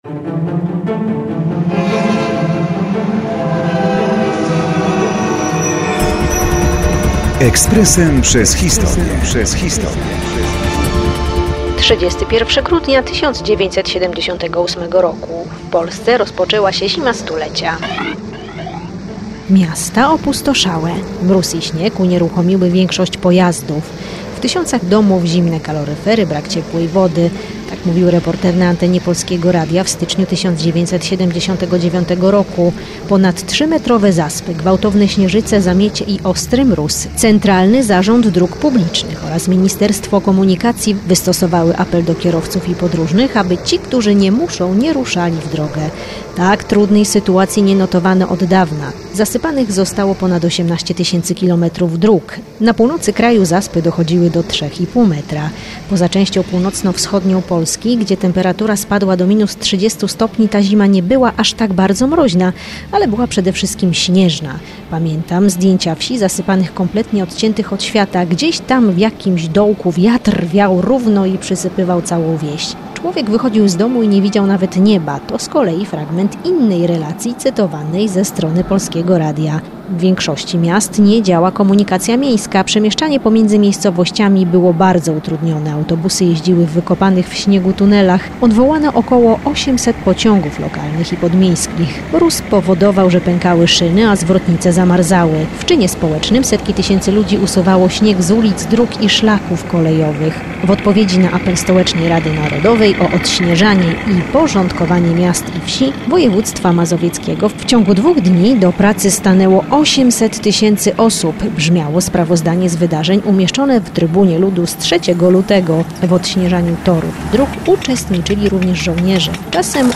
W tysiącach domów zimne kaloryfery, brak ciepłej wody – mówił reporter na antenie Polskiego Radia w styczniu 1979 r.